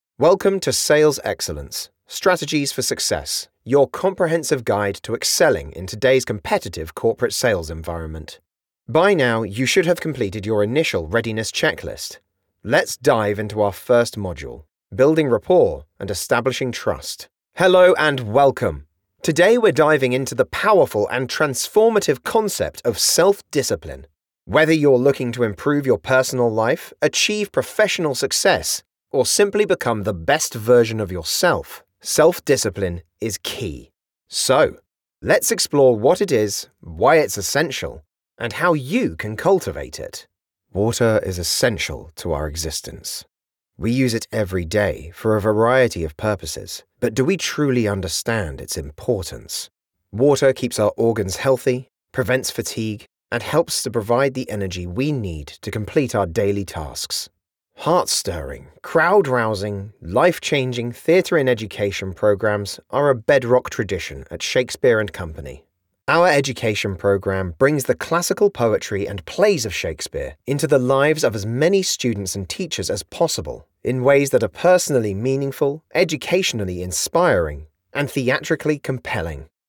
Engels (Brits)
Commercieel, Veelzijdig, Vriendelijk, Natuurlijk, Warm
E-learning